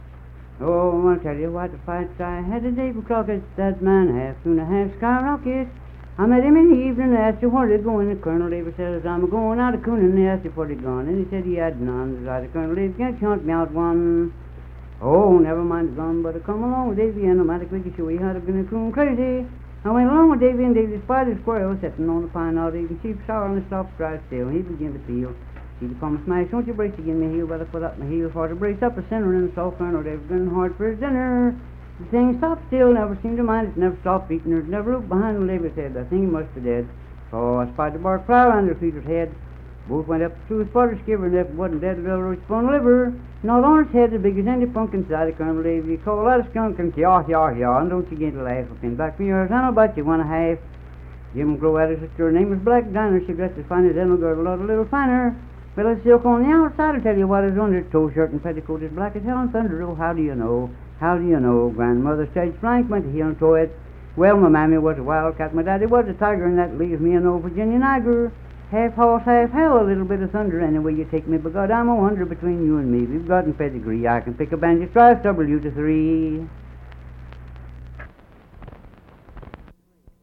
Unaccompanied vocal music
Minstrel, Blackface, and African-American Songs, Cowboys and the Frontier
Voice (sung)
Kirk (W. Va.), Mingo County (W. Va.)